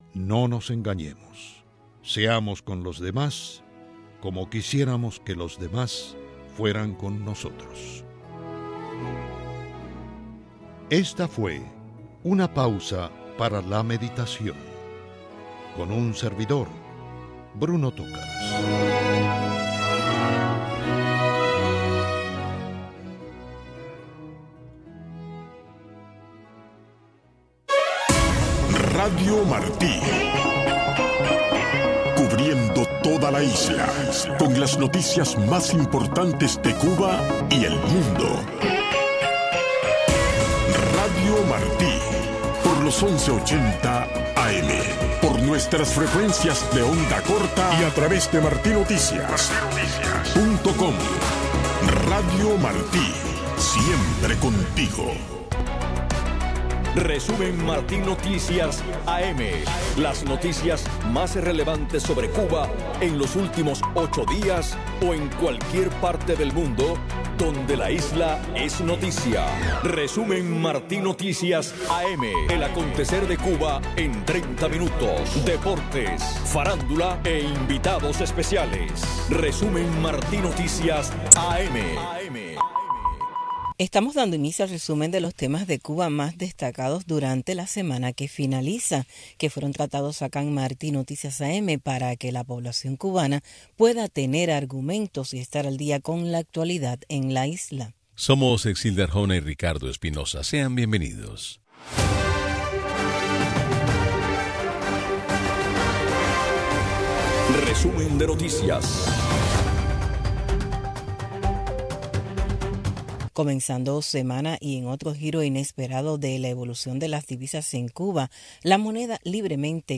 Un resumen ágil y variado con las noticias más relevantes que han ocurrido en Cuba en los últimos 8 días o en cualquier parte del mundo donde un tema sobre la isla es noticia, tratados con invitados especiales. Media hora de información, deportes y farándula.